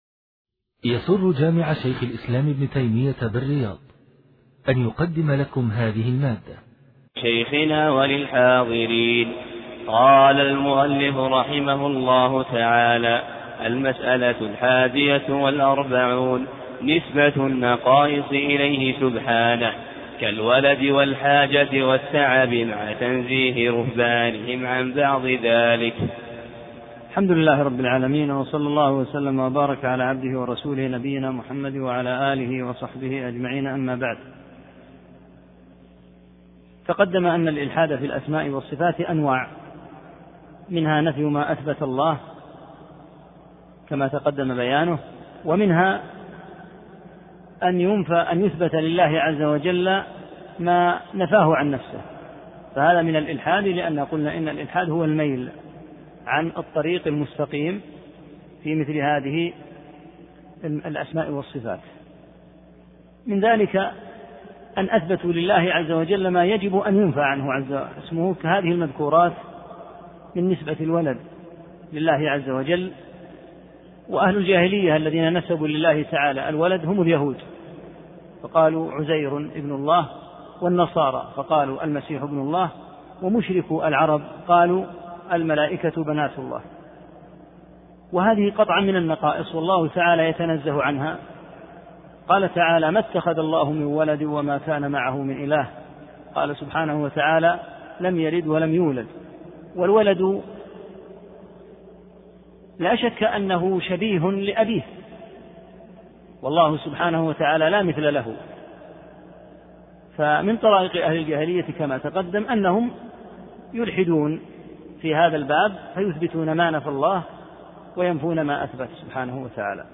4 - الدرس الرابع